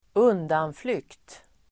Uttal: [²'un:danflyk:t]